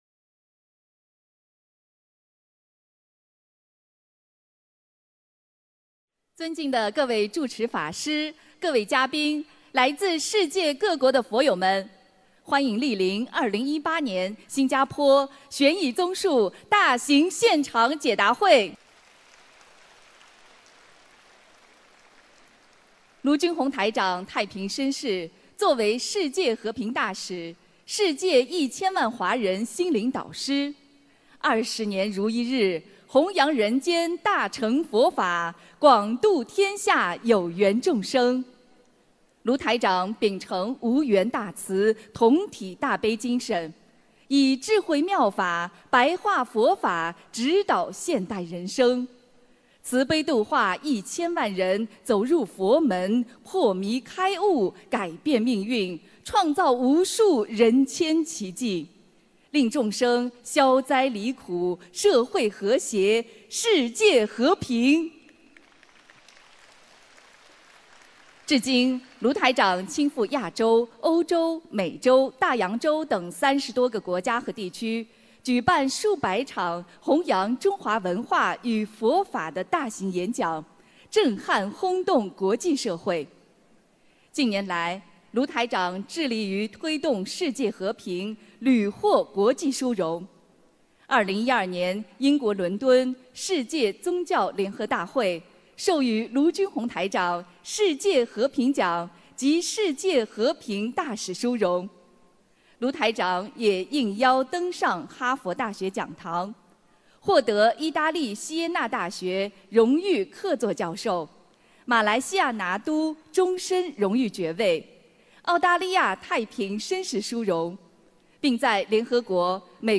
2018年5月19日新加坡法会（视音文图） - 2018年 - 心如菩提 - Powered by Discuz!